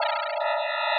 which is the sound generated from and corresponding to the
Soundscapes from The vOICe - Seeing with your Ears!
usflag.wav